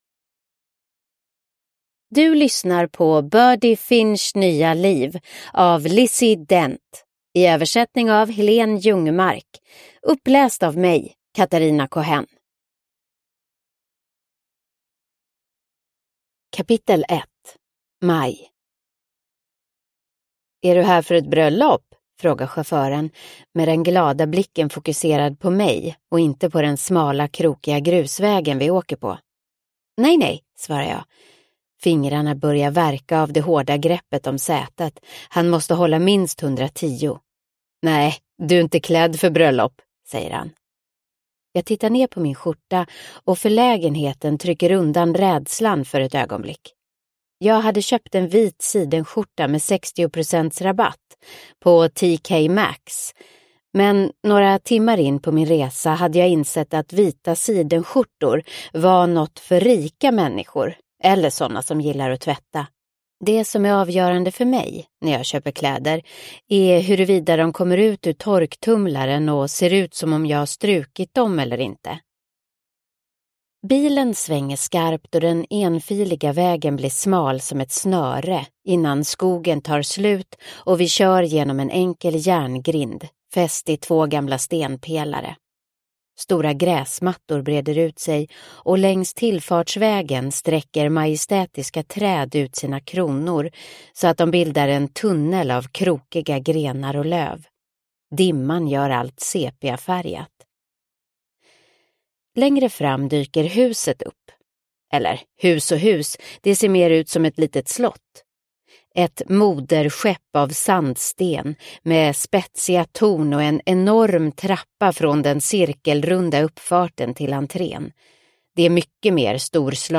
Birdy Finchs nya liv – Ljudbok – Laddas ner